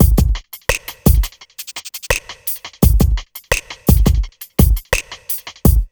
1TI85BEAT4-R.wav